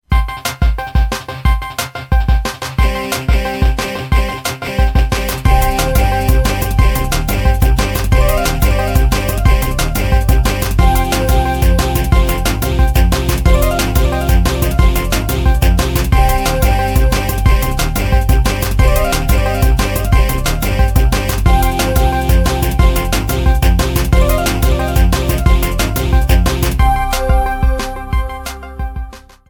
A song
Ripped from the remake's files
trimmed to 29.5 seconds and faded out the last two seconds